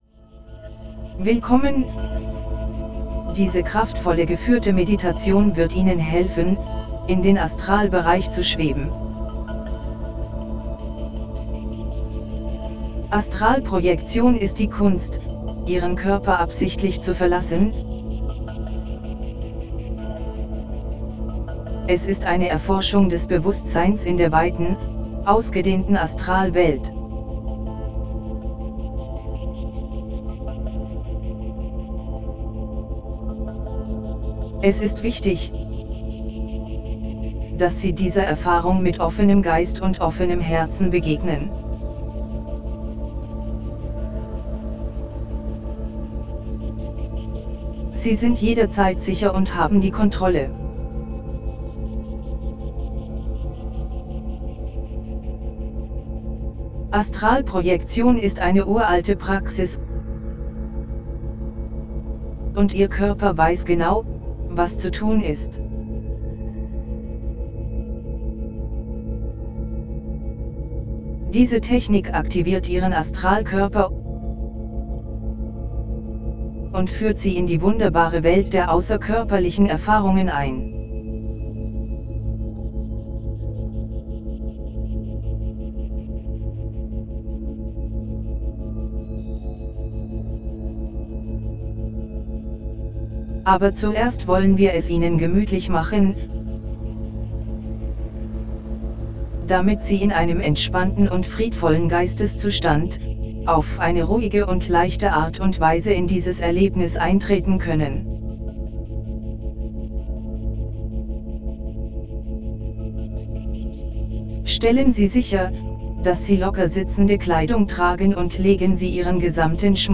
Die Technik des schwebenden Ballons Dieser Leitfaden zur Astralprojektionsmeditation hilft Ihnen bei der kraftvollen Technik des «schwebenden Ballons» Für das beste Erlebnis empfehlen wir die Verwendung von Kopfhörern / Ohrstöpseln, da die Meditation in binaurale 4,5-Hz-Theta-Beats eingebettet ist.